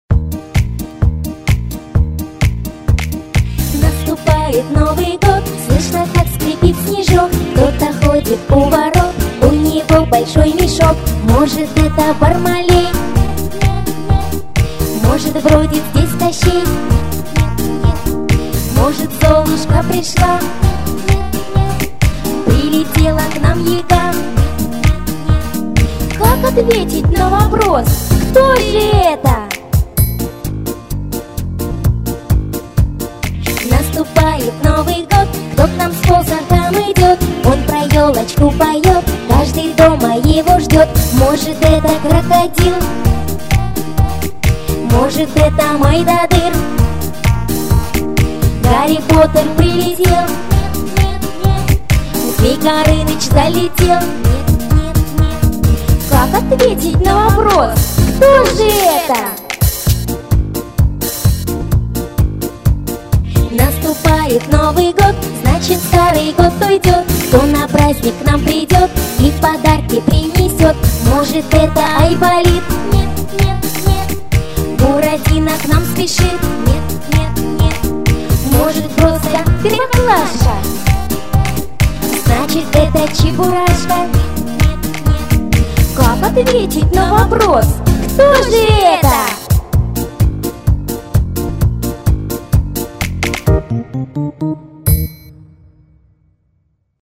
• Категория: Детские песни
Скачать плюс